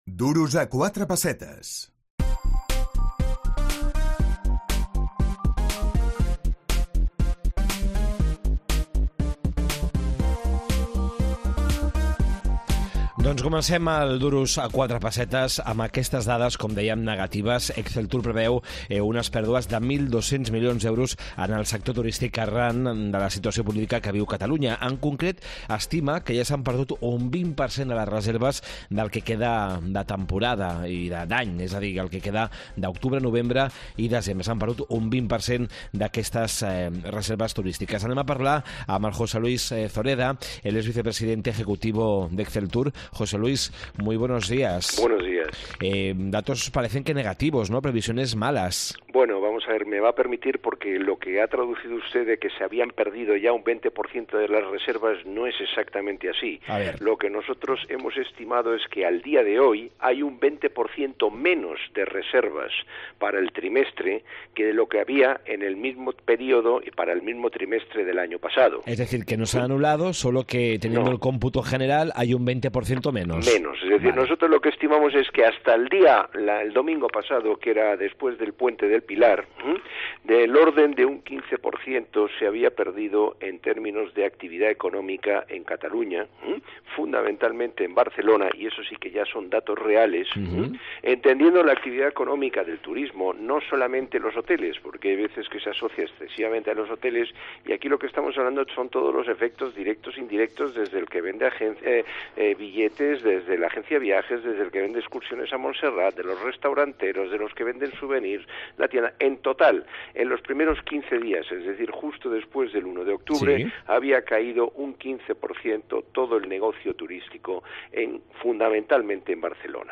Exceltur preveu pèrdues de 1.200 milions per al turisme català si persisteix la caiguda de reserves. Entrevista